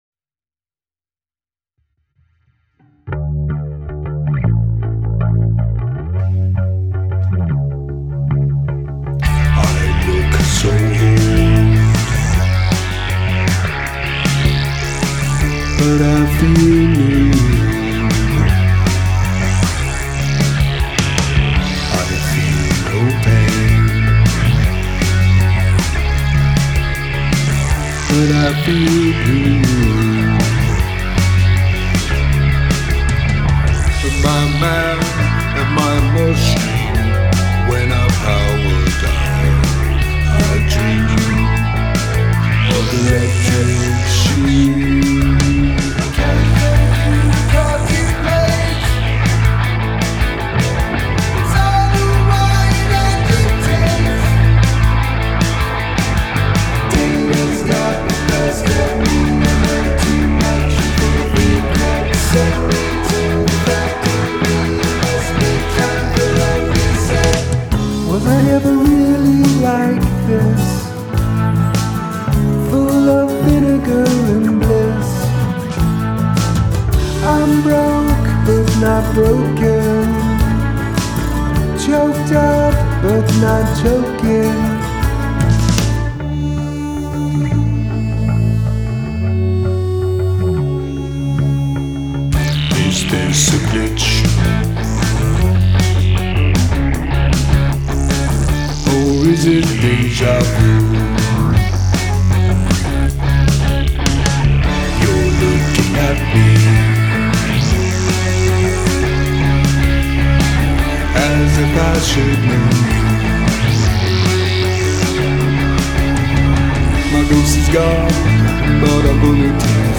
Must include at least one mood shift (musical & lyrical)
Mood 1 in 4/4